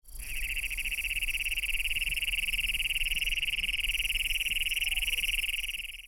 Le crapaud des steppes
Il possède un sac vocal unique se développant, lorsqu'il chante, au delà de son museau.
chant:
Un appel individuel peut durer jusqu'à 50 secondes.
chant_cognatus.mp3